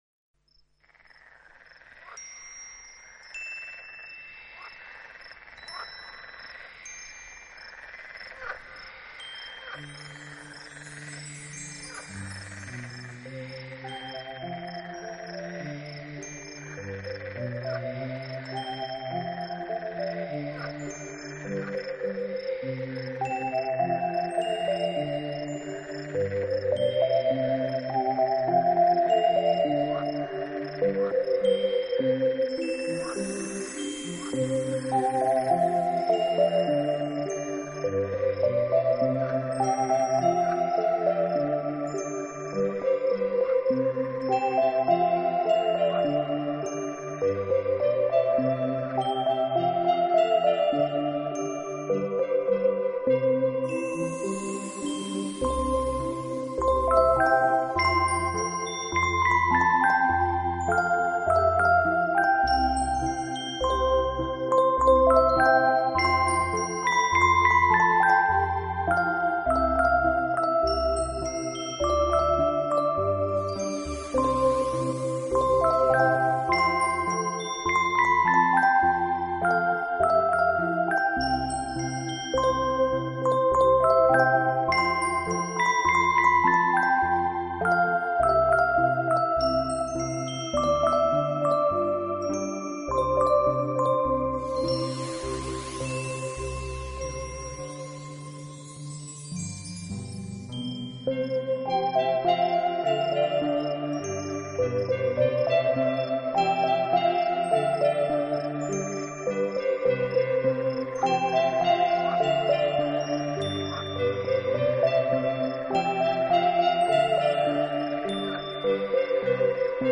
Music with an exceptional quality of innocence and peace.